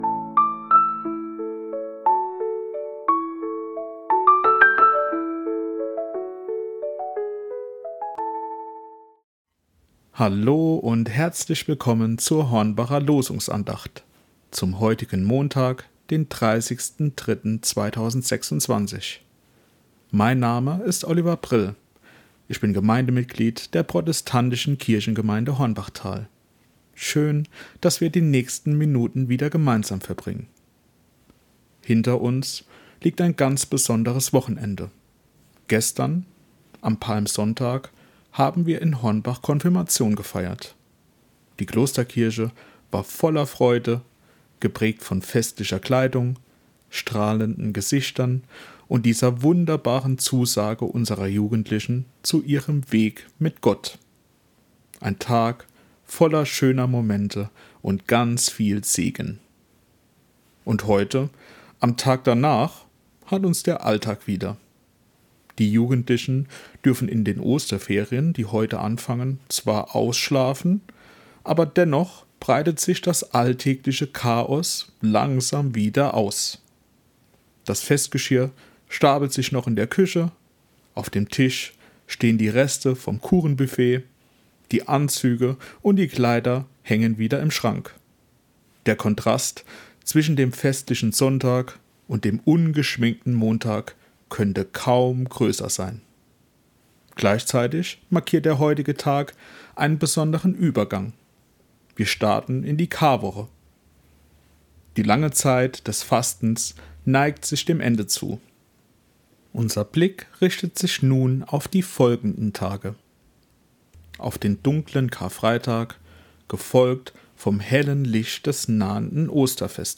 Losungsandacht für Montag, 30.03.2026 – Prot.